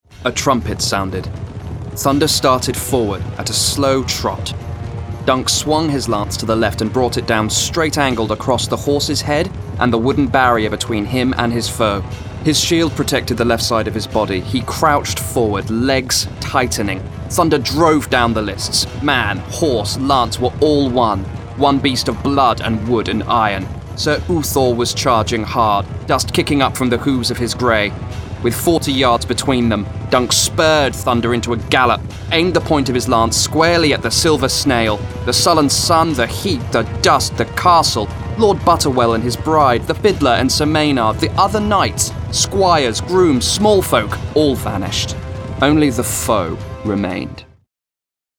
• Male
Hedge Knight. Audio Book, Direct, Confident